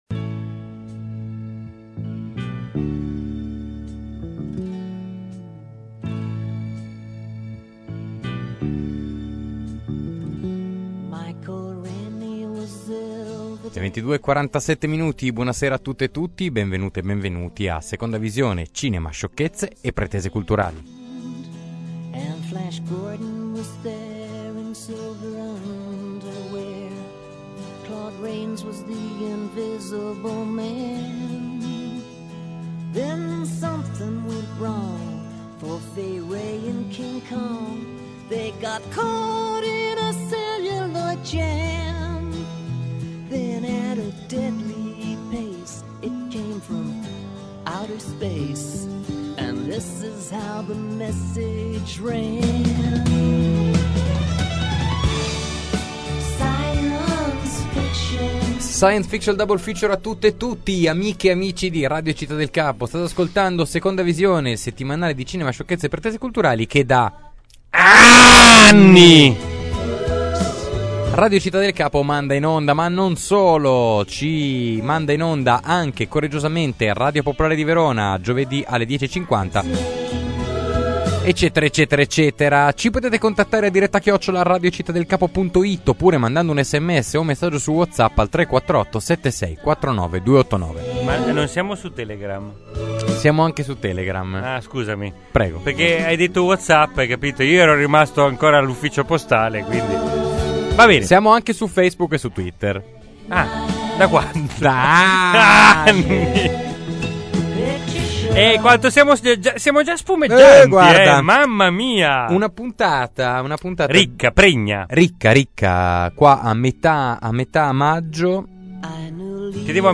intervista con Detto Mariano